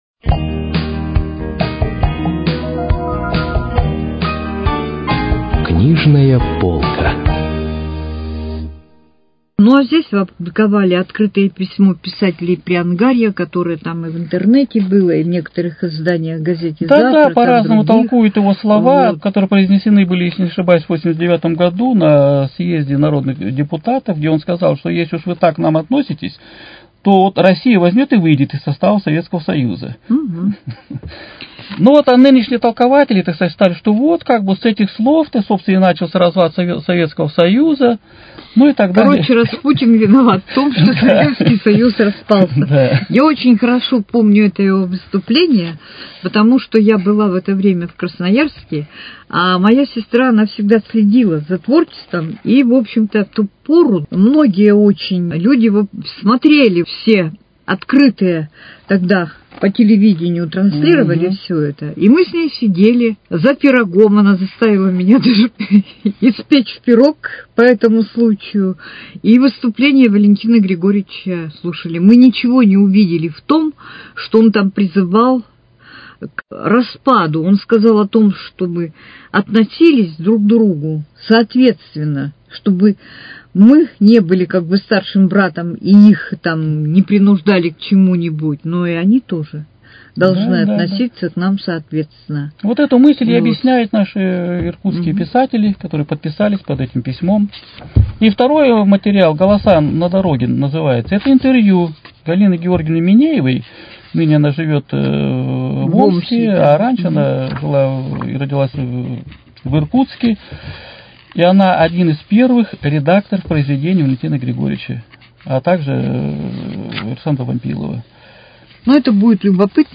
Передача из цикла «Книжная полка».